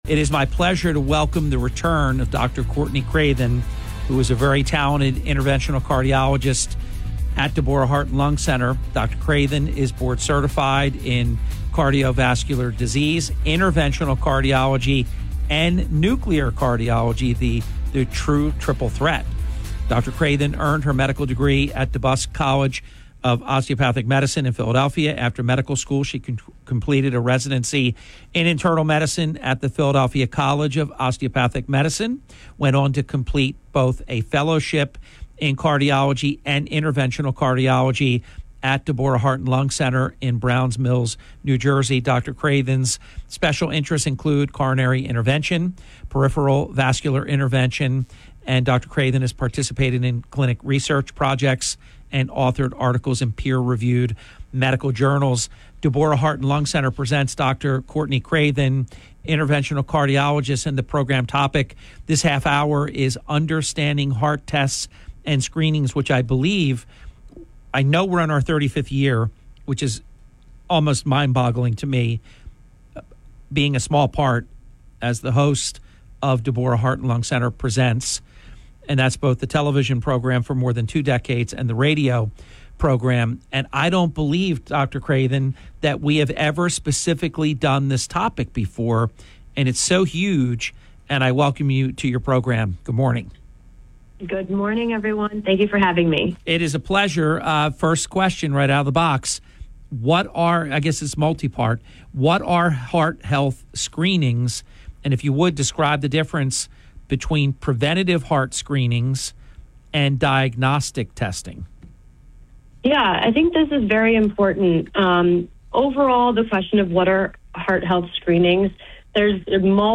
On this episode of the Hurley in the Morning radio show